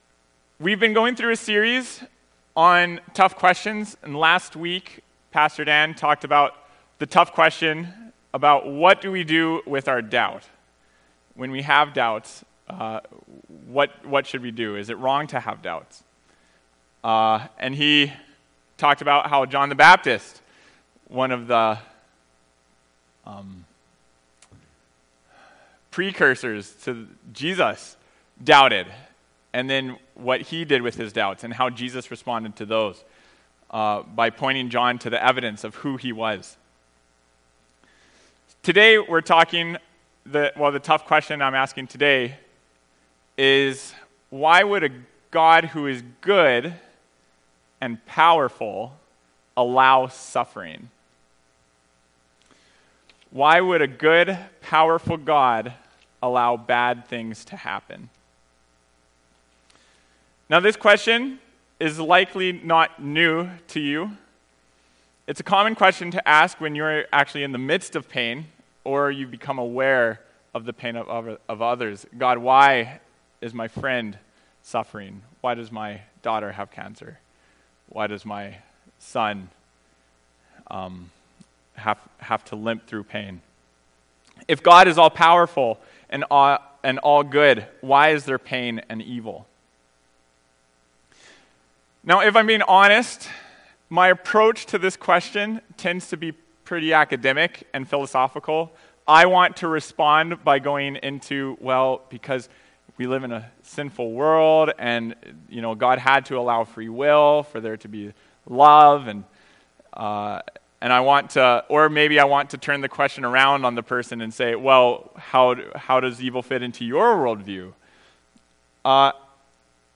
Tough Questions Service Type: Sunday Morning Preacher